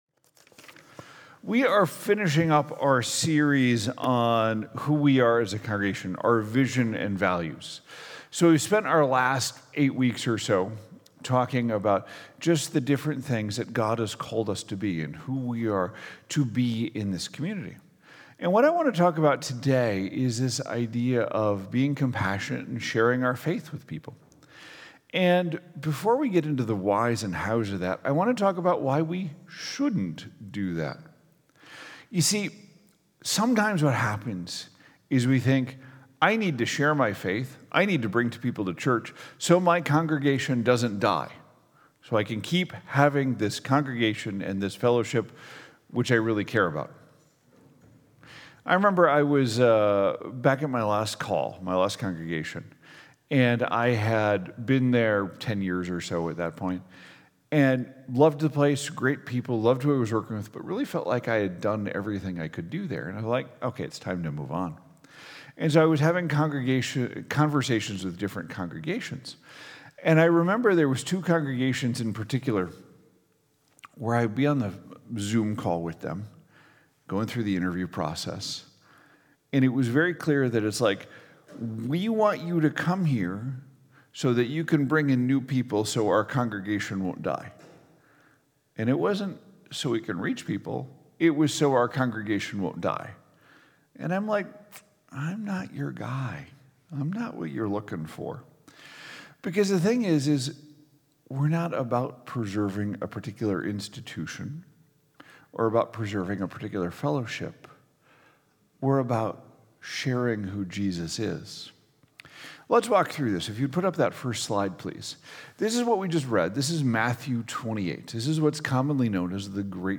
2025 Who Are We every generation sharing God's love Sunday Morning Sermon Series